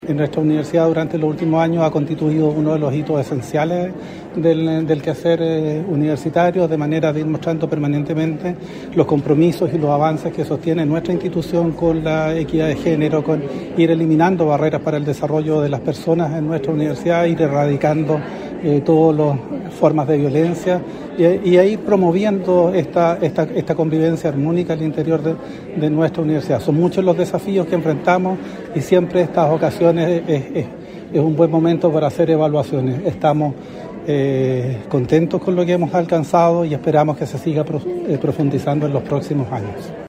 Presidenta de la Corte Suprema encabezó acto de conmemoración del 8M en la UdeC - Radio UdeC